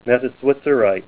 Help on Name Pronunciation: Name Pronunciation: Metaswitzerite + Pronunciation